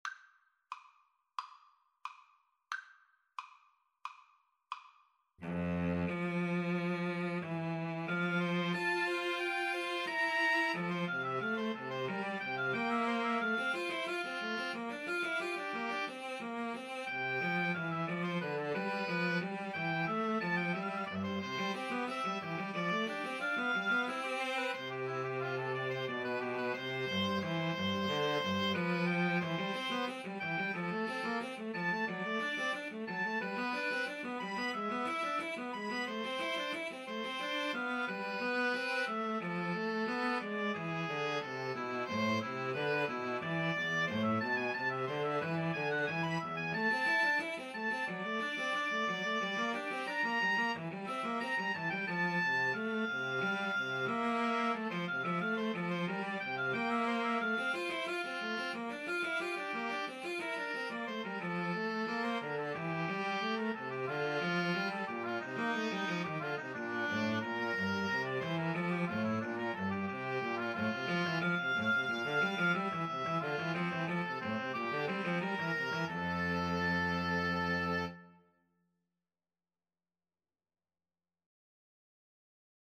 OboeViolinCello
F major (Sounding Pitch) (View more F major Music for Mixed Trio )
Classical (View more Classical Mixed Trio Music)